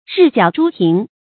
日角珠庭 rì jiǎo zhū tíng
日角珠庭发音
成语注音 ㄖㄧˋ ㄐㄧㄠˇ ㄓㄨ ㄊㄧㄥˊ